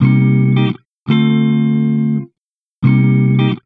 Index of /90_sSampleCDs/Zero-G - Total Drum Bass/Instruments - 1/track35 (Guitars)
01 Council Road 170 G.wav